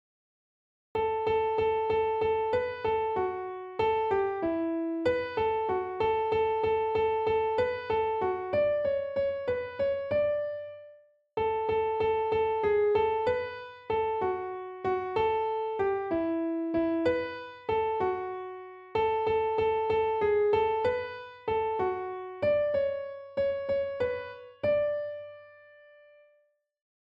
Reinforces beat in contrasting time signatures.